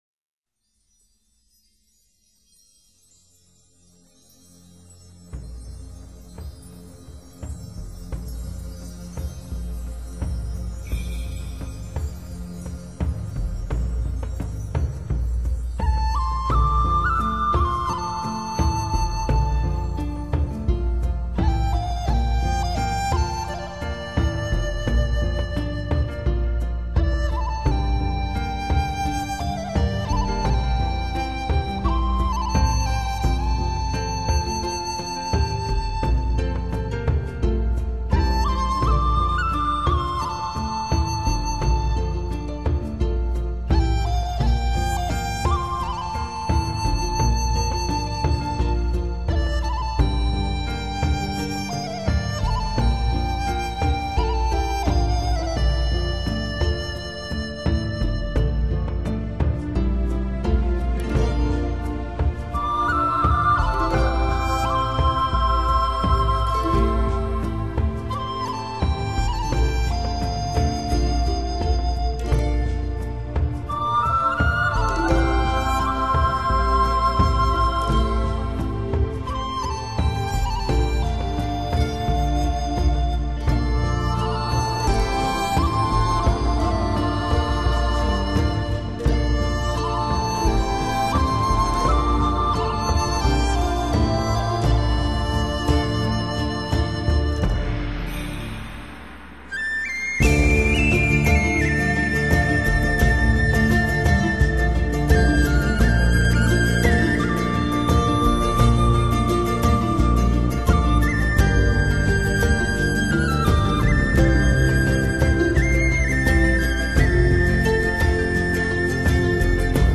笛声高亢的，婉转的，仿佛要冲破夜色似的，久久盘旋不绝.......月，只在今晚，陪我一夜吧......！